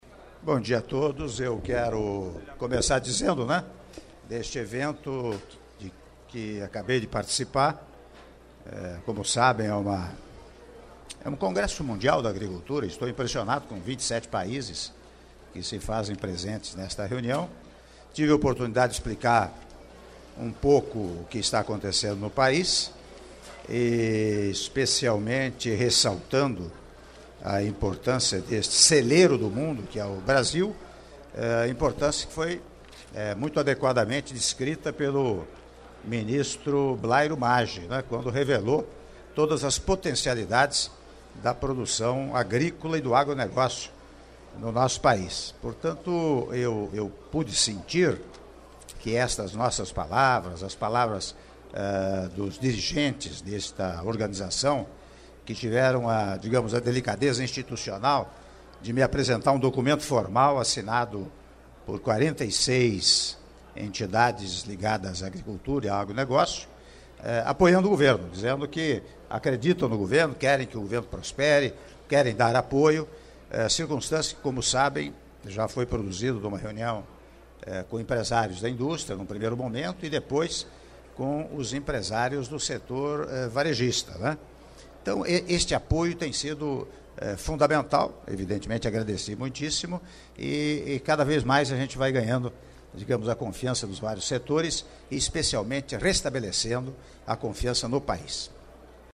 Áudio da entrevista do Presidente da República, Michel Temer, após cerimônia de Abertura Oficial do Global Agribusiness Forum 2016 - São Paulo/SP (01min39s) — Biblioteca